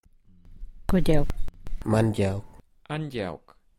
Pronunciation
Listen to the audio file and pay attention to the diphthong written ʼewʼ [ɛu] "A diphthong, also known as a gliding vowel, is a combination of two adjacent vowel sounds within the same syllable.